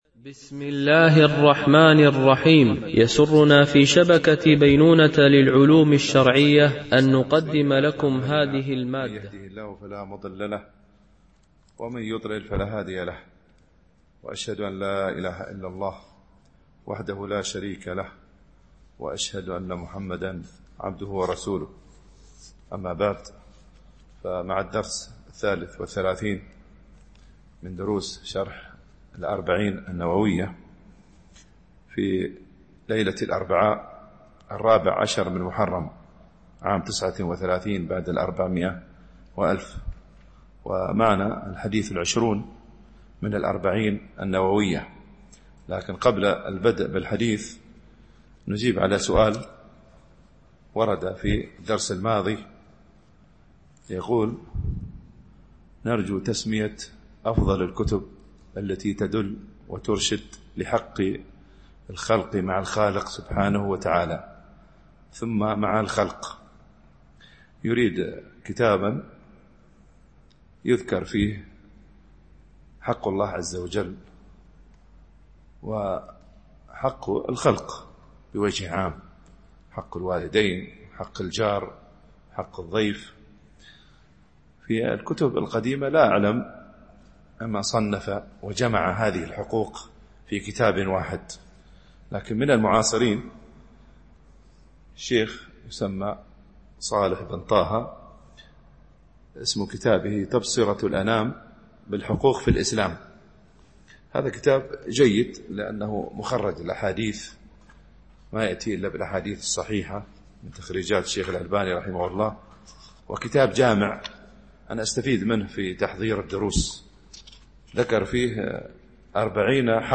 شرح الأربعين النووية ـ الدرس 33 (الحديث 20)